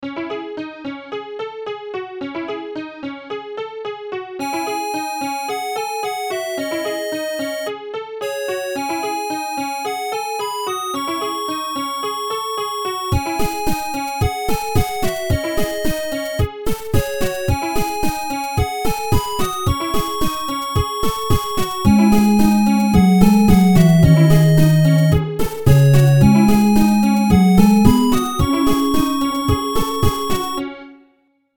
a cool little 8-bit song i made - Electronic - Young Composers Music Forum
a cool little 8-bit song i made